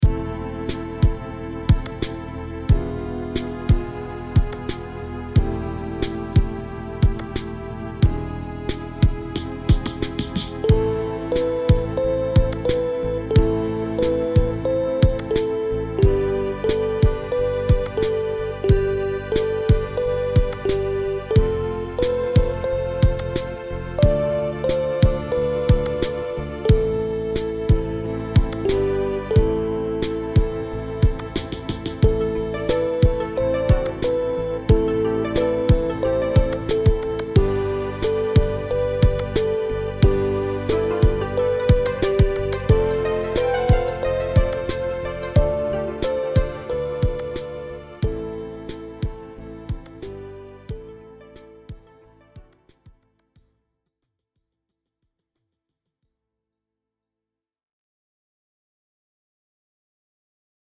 Maza dziesmiņa Play-along.
Spied šeit, lai paklausītos Demo ar melodiju